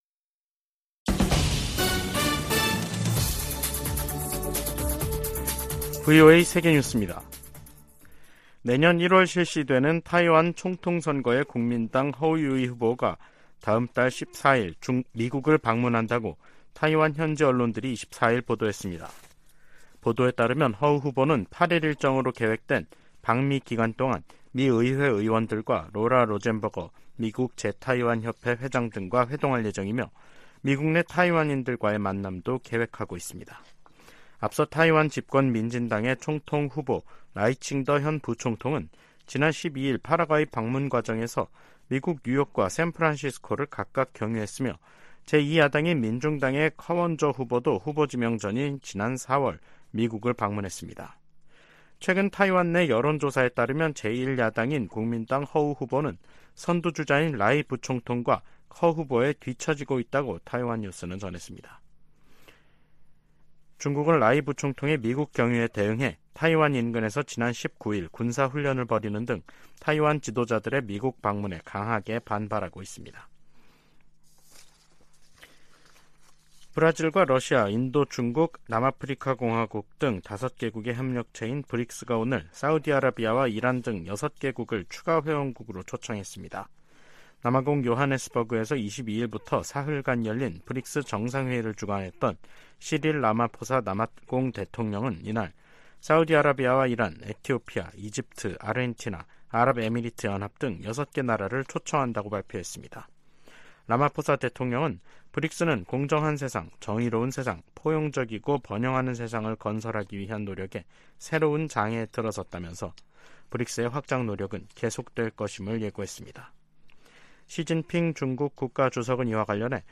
VOA 한국어 간판 뉴스 프로그램 '뉴스 투데이', 2023년 8월 24일 3부 방송입니다. 북한이 2차 군사 정찰위성 발사를 시도했지만 또 실패했습니다. 백악관은 북한 위성 발사가 안보리 결의에 위배된다고 규탄하며 필요한 모든 조치를 취할 것이라고 밝혔습니다. 미 국방부가 생물무기를 계속 개발하는 국가 중 하나로 북한을 지목했습니다.